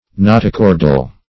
Search Result for " notochordal" : The Collaborative International Dictionary of English v.0.48: Notochordal \No`to*chor"dal\, a. (Anat.) Of or pertaining to the notochord; having a notochord.